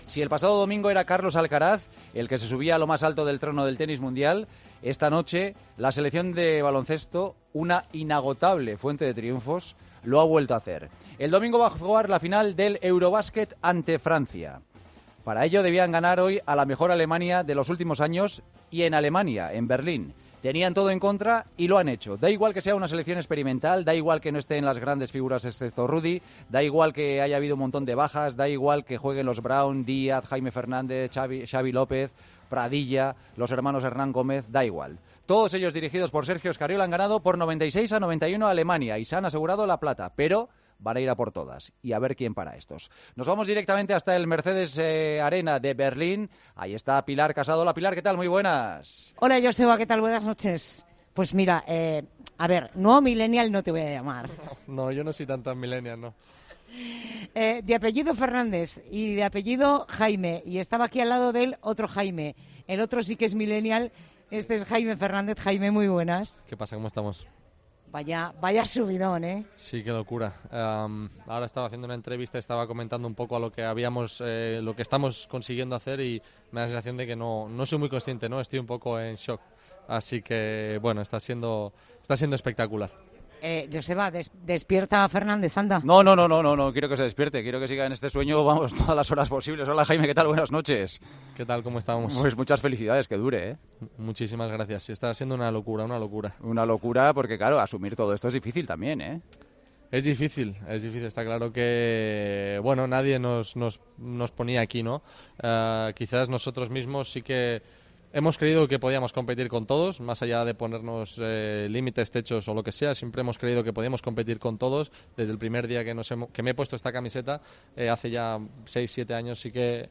En El Partidazo de COPE hablamos con el jugador de la selección tras meterse en la final del Eurobasket: "Rudy es nuestro faro y Scariolo es un entrenador espectacular".